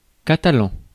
Ääntäminen
Synonyymit langue de Lulle valencien lactaire délicieux Ääntäminen France Tuntematon aksentti: IPA: /ka.ta.lɑ̃/ Haettu sana löytyi näillä lähdekielillä: ranska Käännös Ääninäyte Adjektiivit 1.